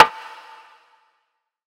Metro Perc Snare Reverb.wav